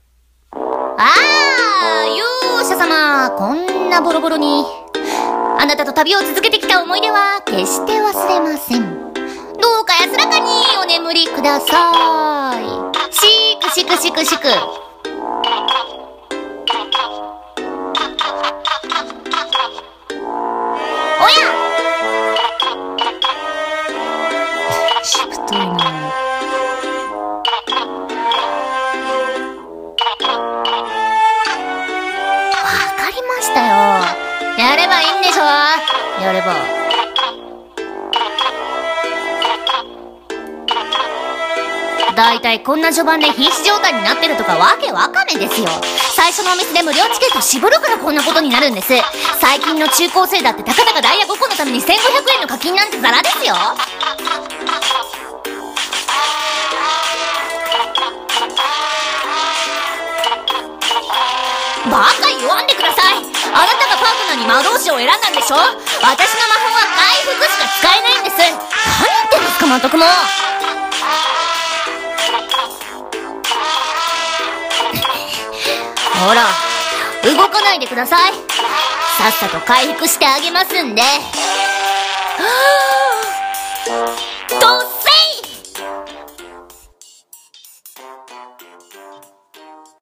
【声劇】ドッセイ【台本】